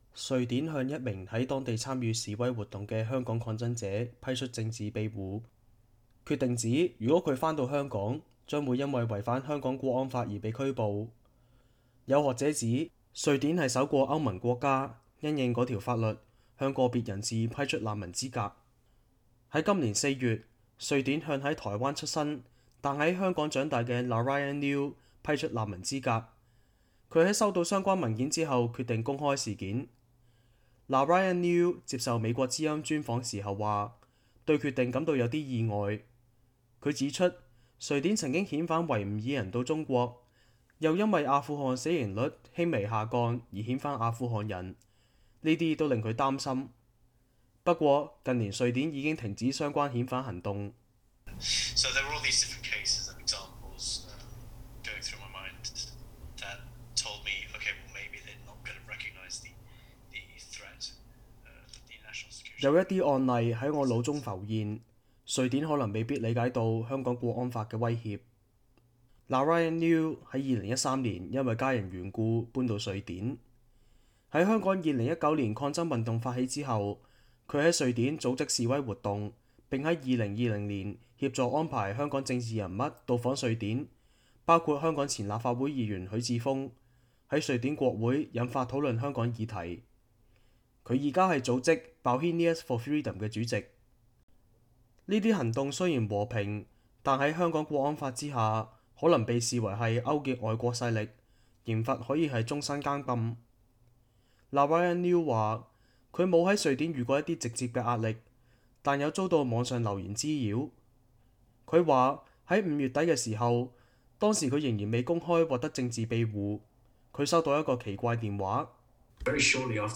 專訪：香港抗爭者於瑞典獲政治庇護 寄望當地保護更多港人